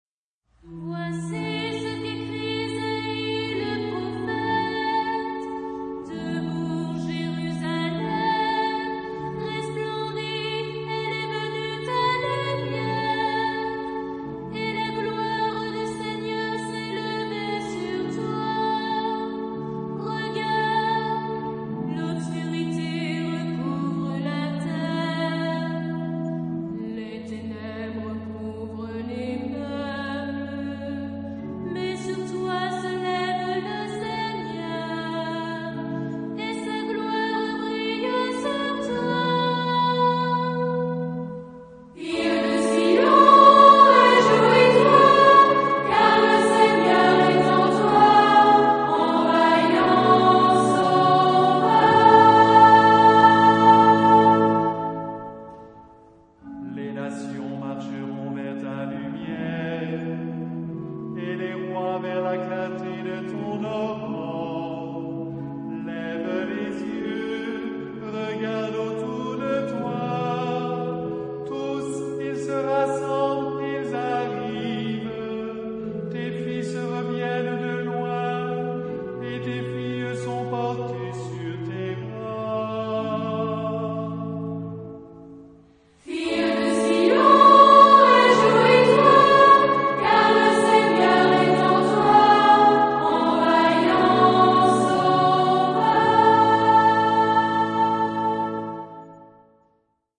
Genre-Style-Forme : Récitatif
Caractère de la pièce : vivant
Type de choeur : unisson
Solistes : Ténor (1) OU Soprano (1)  (1 soliste(s))
Instrumentation : Orgue
Tonalité : si bémol majeur